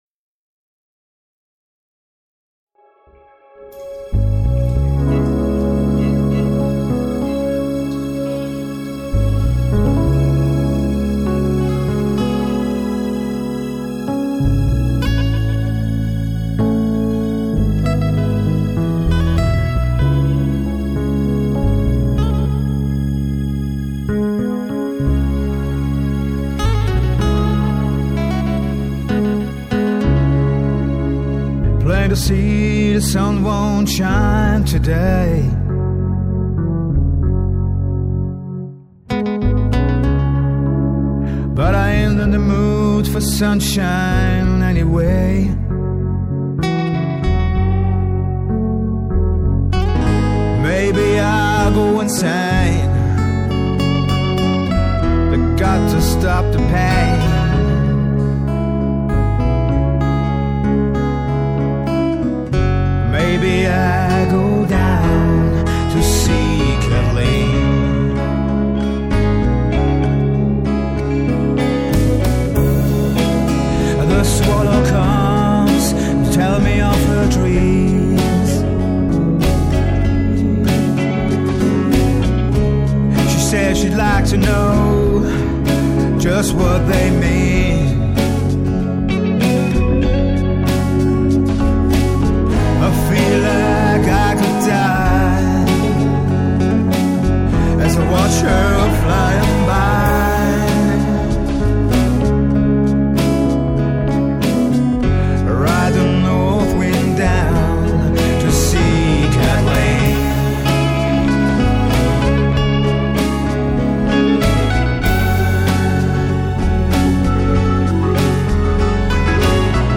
keyboards & violine